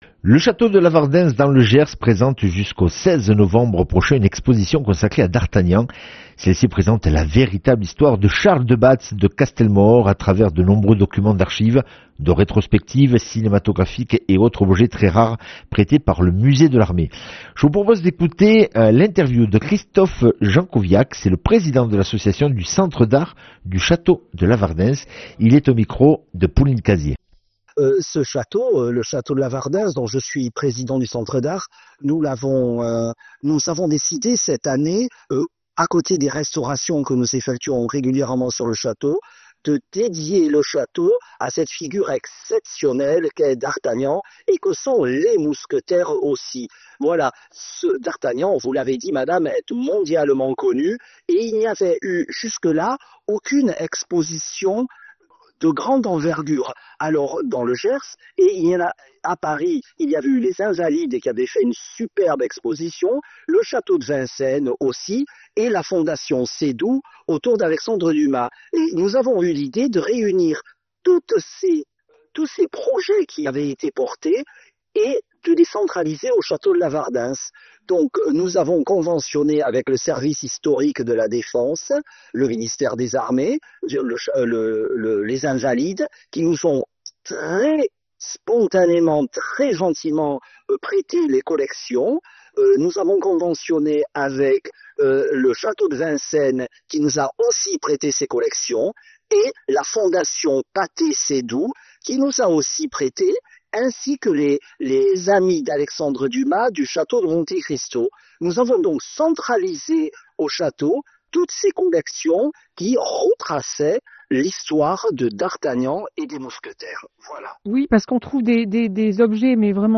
Accueil \ Emissions \ Information \ Locale \ Interview et reportage \ Exposition consacrée à d'Artagnan au chateau de Lavardens dans le Gers.